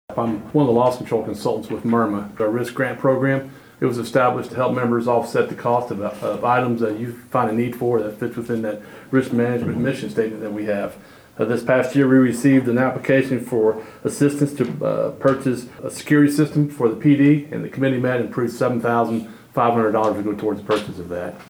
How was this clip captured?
A Risk Management grant was presented to the Chillicothe Police Department by the City’s Risk Management insurer, MIRMA. At Monday’s City Council meeting